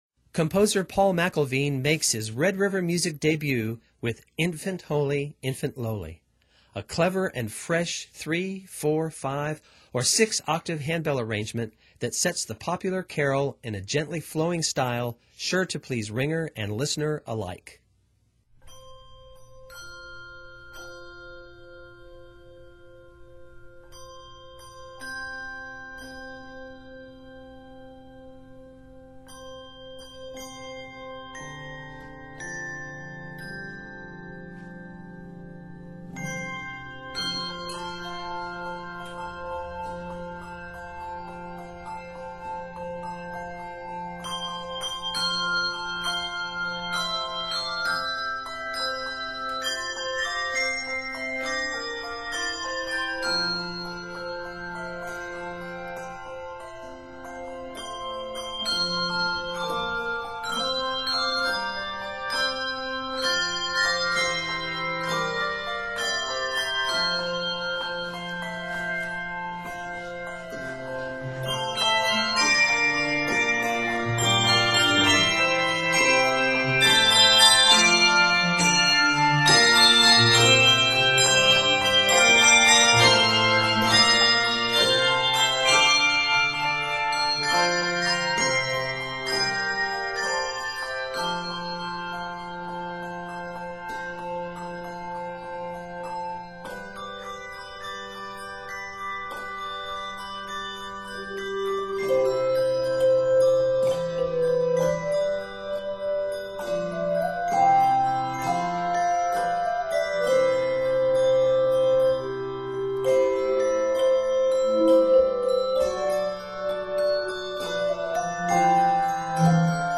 sets this popular carol in a gently flowing style